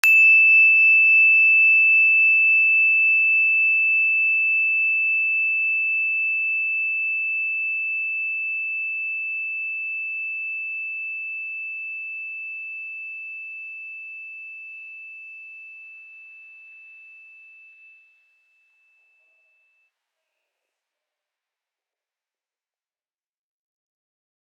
energychime_plastic-E6-mf.wav